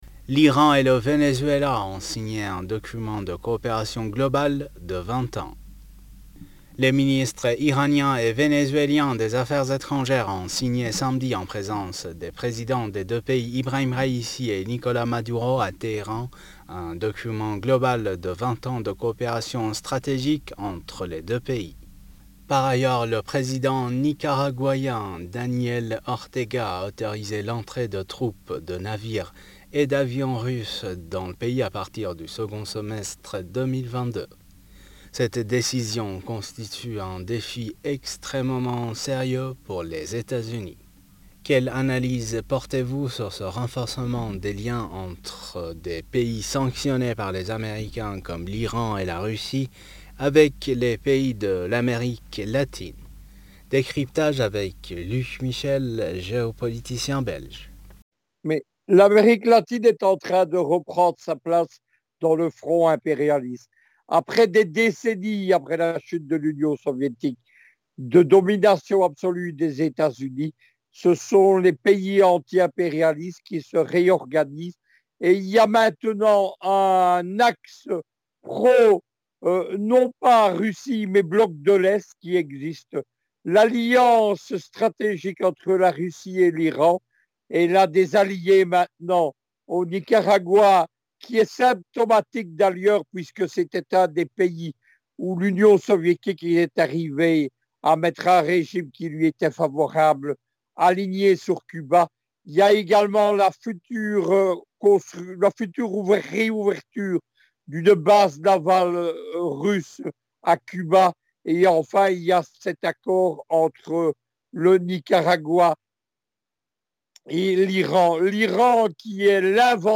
géopoliticien belge s’exprime sur le sujet.